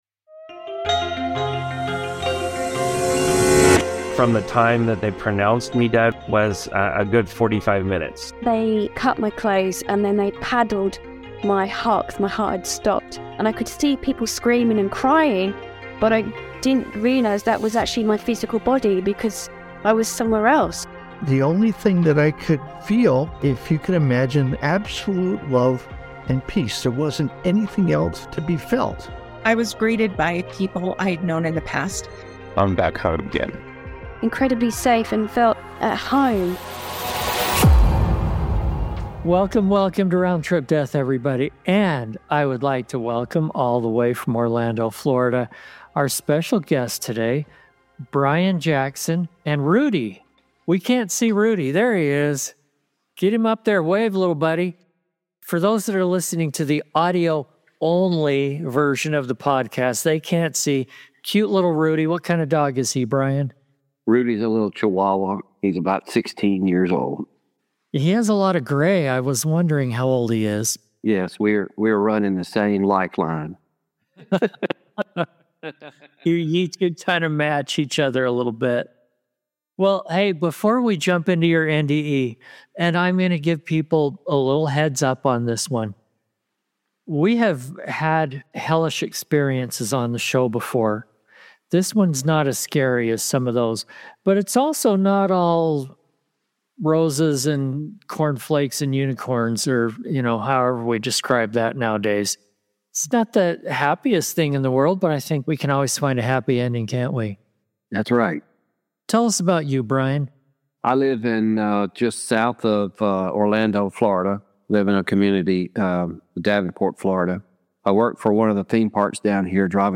Round Trip Death podcast features discussions with people who have actually died, visited the other side, and returned to talk about it.